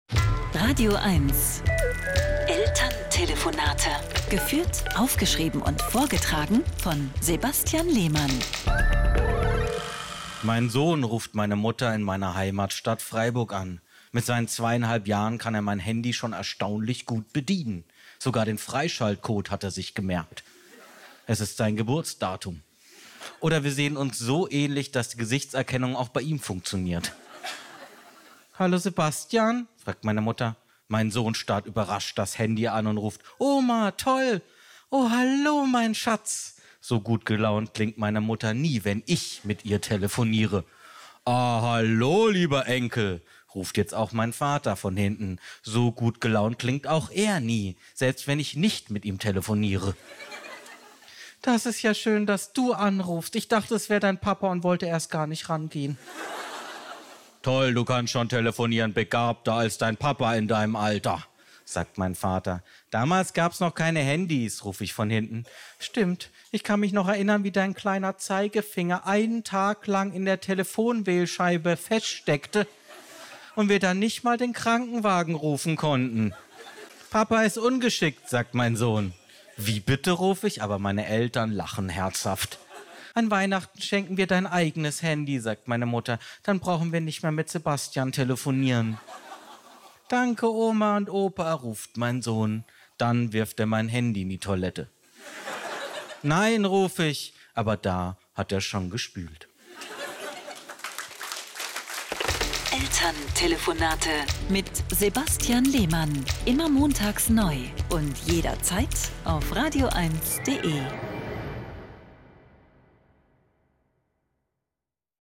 Saubere Komödien Komiker Chat radioeins (Rundfunk Berlin-Brandenburg Komödie Unterhaltung